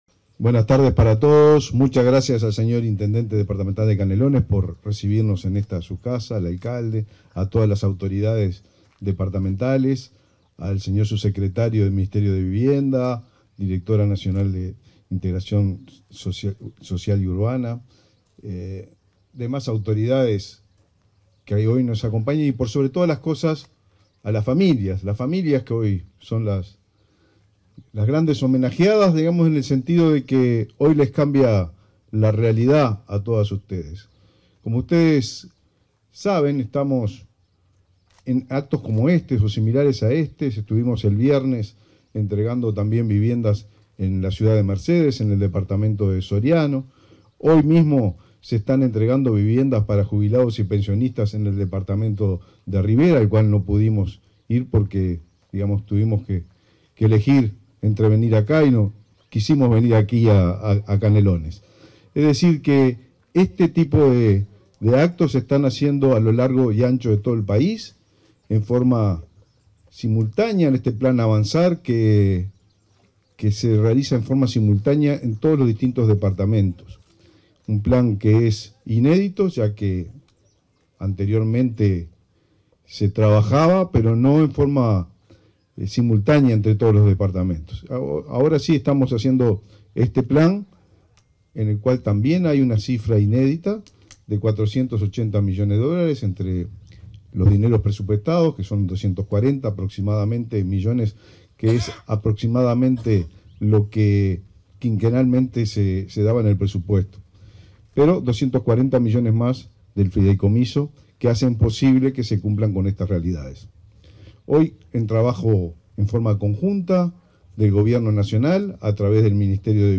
En el marco de la ceremonia de entrega de 10 soluciones habitacionales como parte del proyecto de intervención en el asentamiento Villa Monte, en La Paz, este 9 de octubre, se expresaron el ministro de Vivienda y Ordenamiento Territorial, Raúl Lozano, y la directora nacional de Integración Social y Urbana, Florencia Arbeleche.